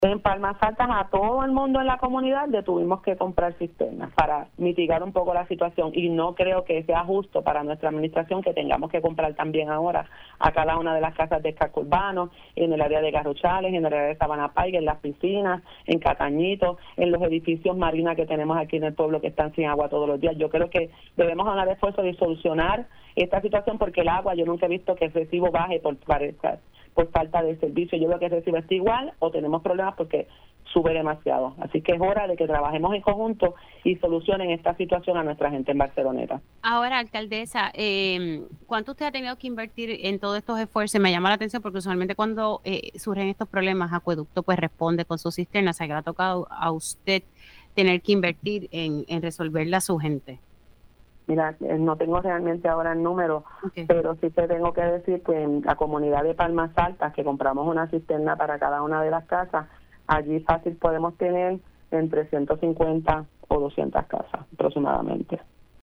La alcaldesa de Barceloneta, Wanda Soler indicó en Pega’os en la Mañana que tuvo que declarar un estado de emergencia en su municipio debido a la emergente falta de agua potable a través de todo el pueblo.